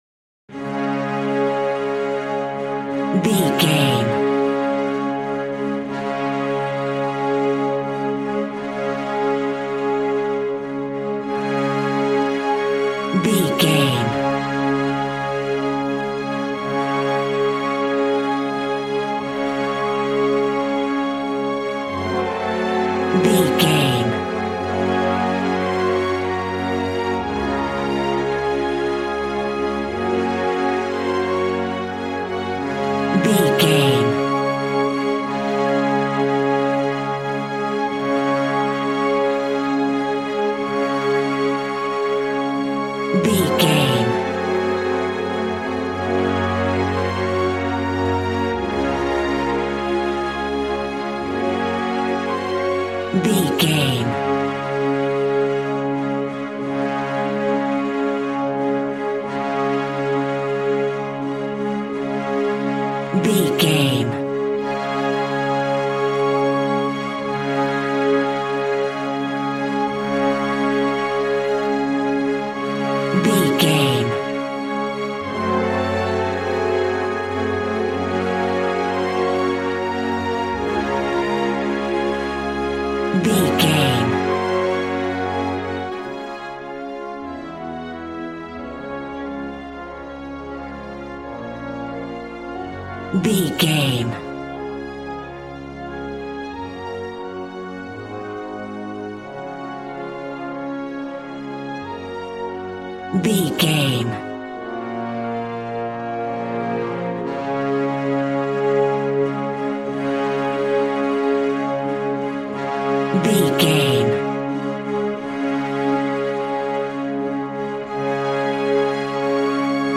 Ionian/Major
D♭
regal
cello
double bass